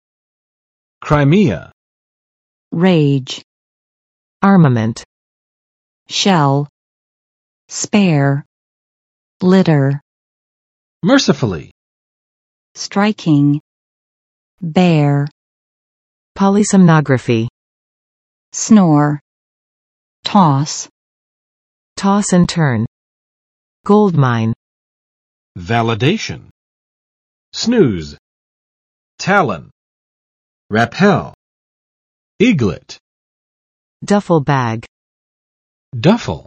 [kraɪˋmiə] n. 克里米亚（半岛）
[redʒ] v. 激烈进行